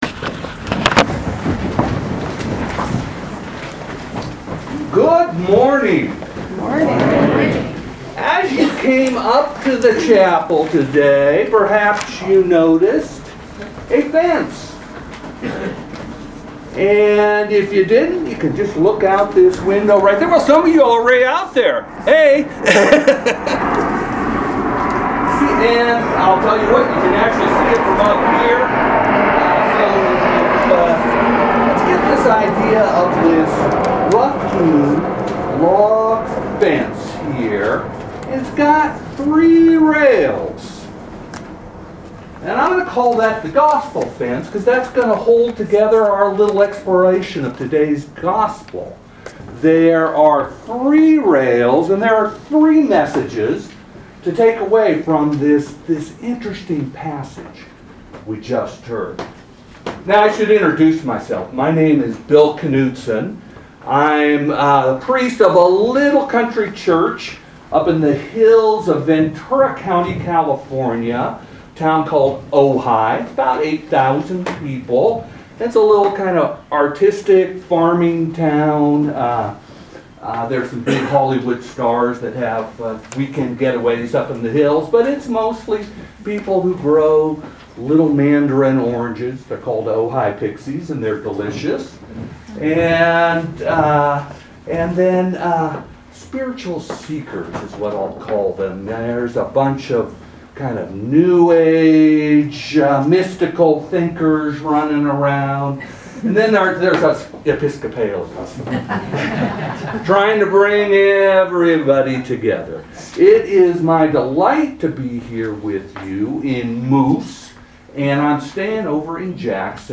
Proper 9 at Chapel of the Transfiguration
Sermons